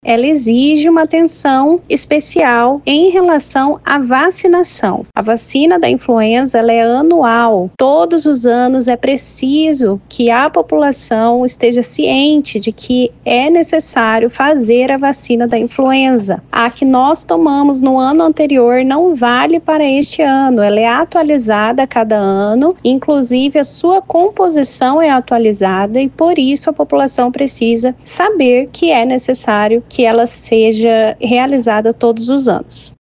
Em entrevista ao Agora 104 da FM Educativa MS, as autoridades de saúde, não há motivo para pânico, mas o cenário acende um alerta para a importância da prevenção e dos cuidados com a saúde.